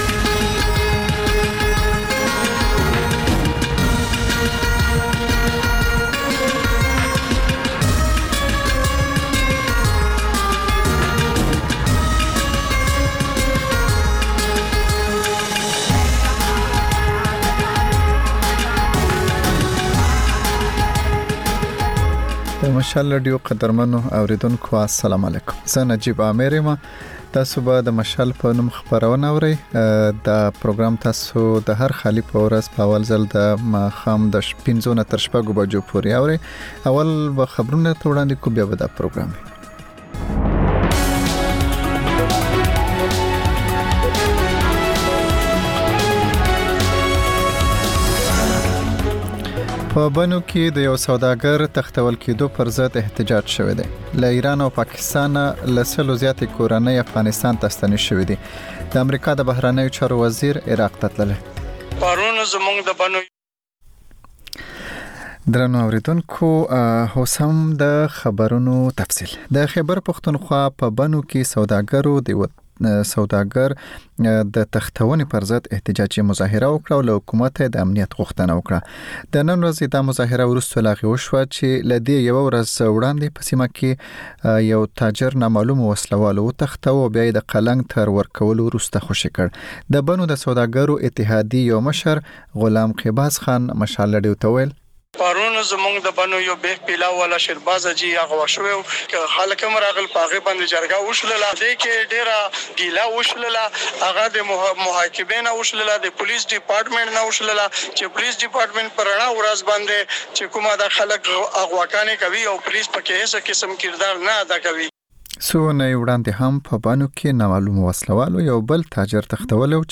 د مشال راډیو ماښامنۍ خپرونه. د خپرونې پیل له خبرونو کېږي، بیا ورپسې رپورټونه خپرېږي.
ځینې ورځې دا ماښامنۍ خپرونه مو یوې ژوندۍ اوونیزې خپرونې ته ځانګړې کړې وي چې تر خبرونو سمدستي وروسته خپرېږي.